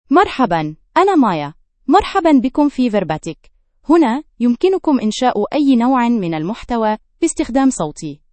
MayaFemale Arabic AI voice
Maya is a female AI voice for Arabic (Standard).
Voice sample
Listen to Maya's female Arabic voice.
Maya delivers clear pronunciation with authentic Standard Arabic intonation, making your content sound professionally produced.